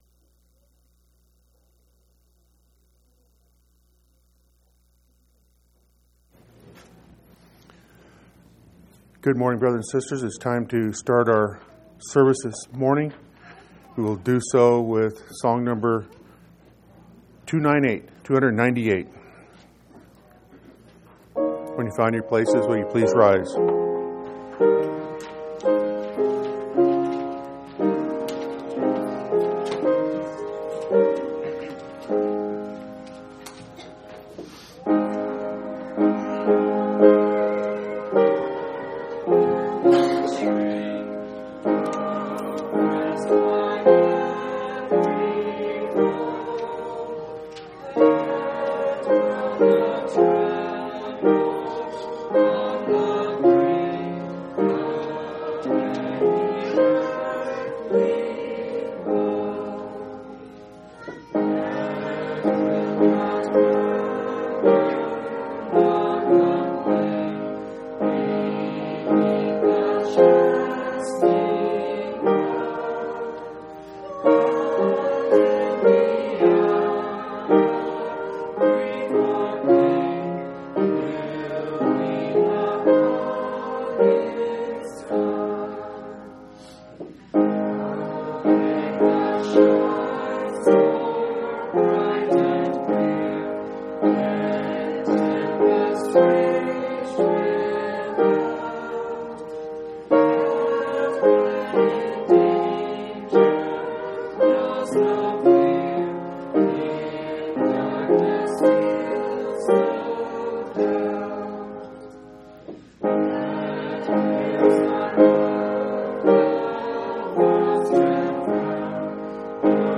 7/13/2003 Location: Phoenix Local Event